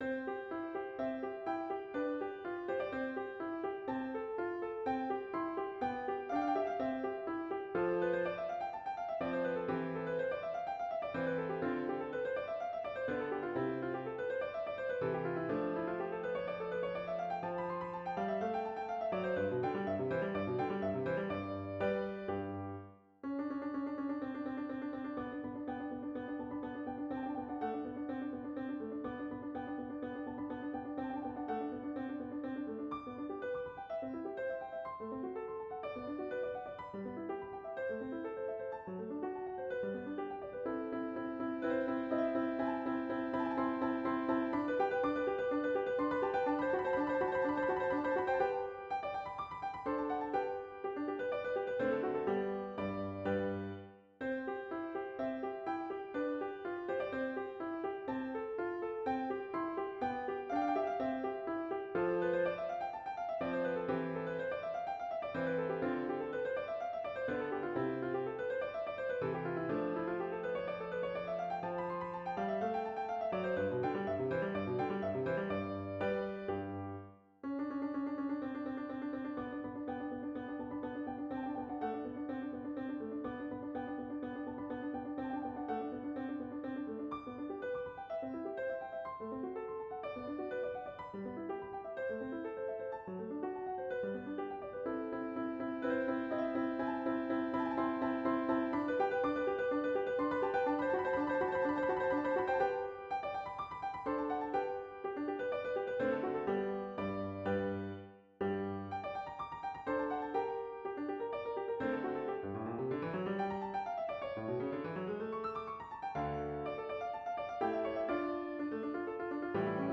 まず、譜読みのしやすい明快なハ長調。
そして、音階を多く取り込んだきめ細やかな旋律。
さらに、徐々に華やかさと重厚感を増し、オーケストラ的なボリュームある和音で盛り上げるクライマックス。